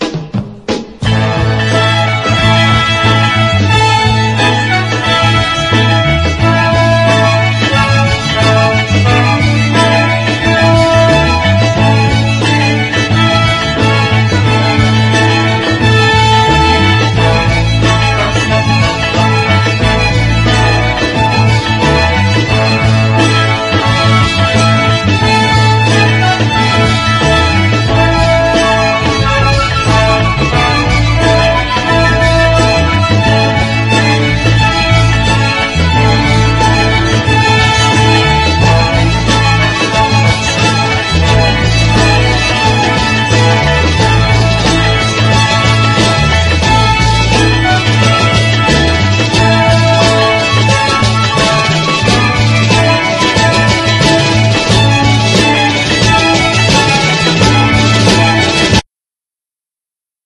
EASY LISTENING / OST / CHILDREN